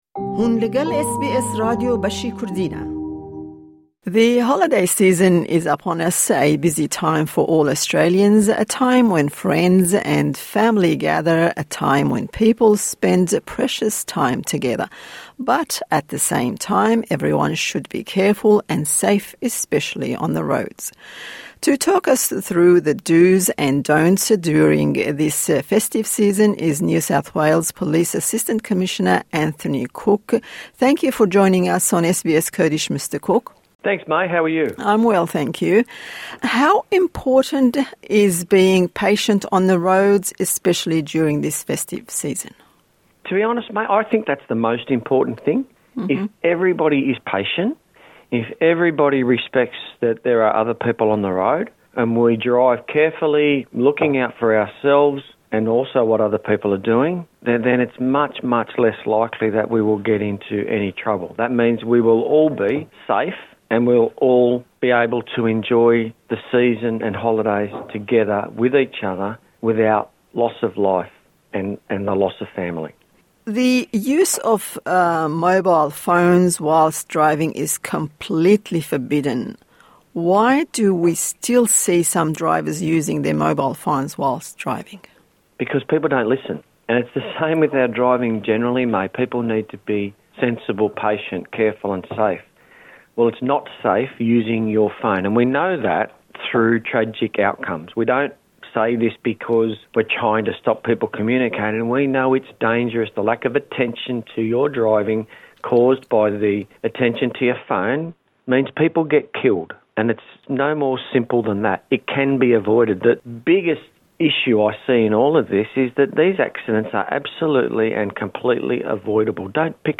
But at the same time, everyone should be careful and safe, especially on the road. To talk us through the dos and don'ts during this festive season is NSW Police Assistant Commissioner Anthony Cooke.